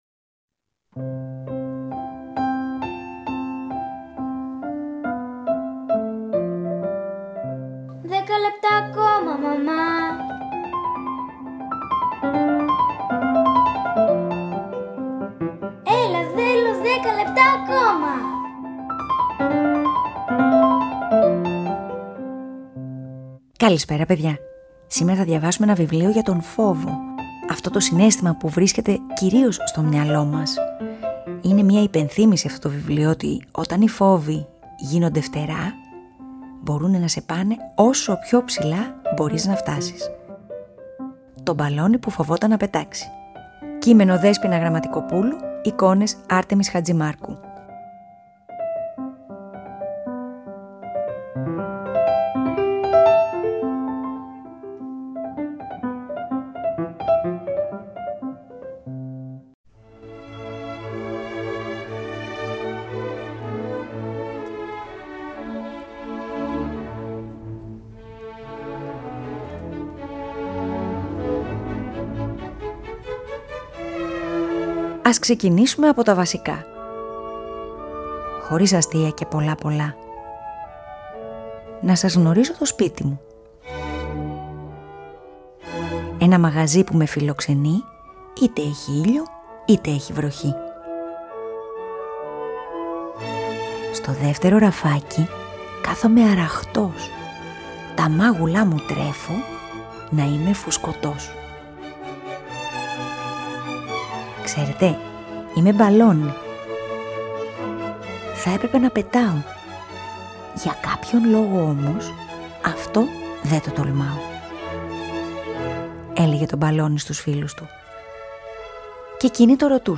Αφήγηση-Μουσικές επιλογές
ΠΑΡΑΜΥΘΙΑ